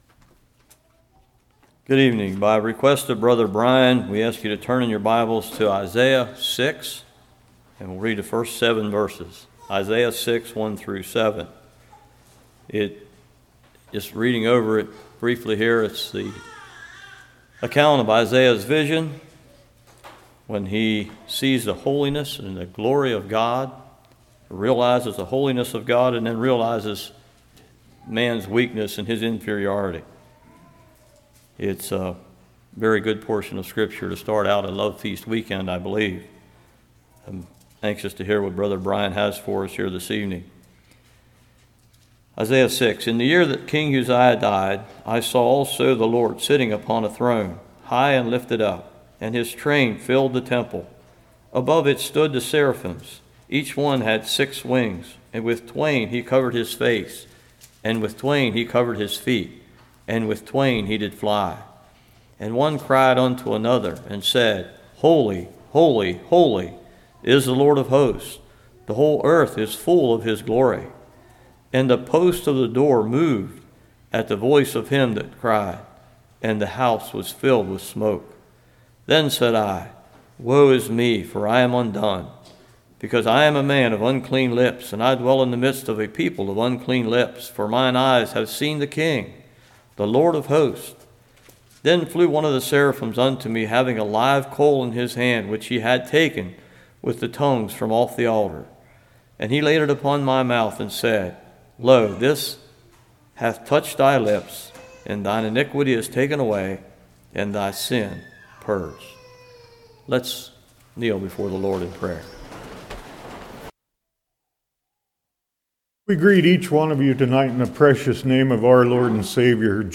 Series: Fall Lovefeast 2020
Service Type: Evening